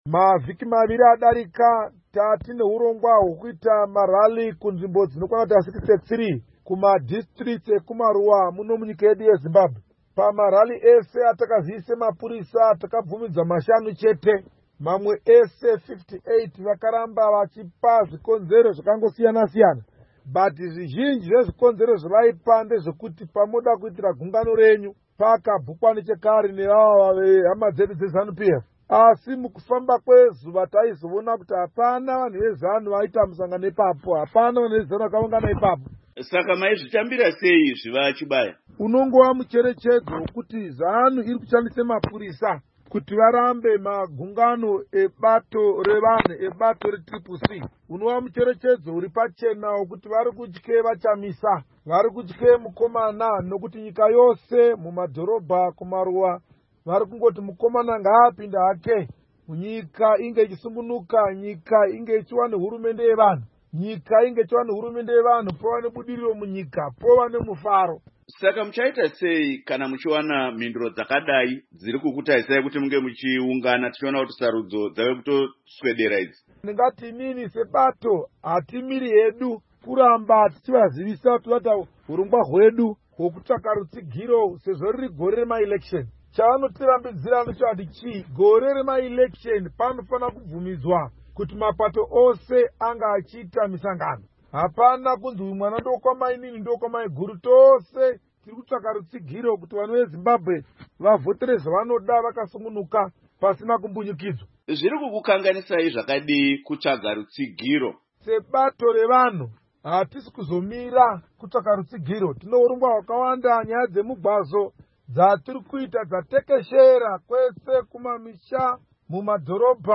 Hurukuro naVaAmos Chibaya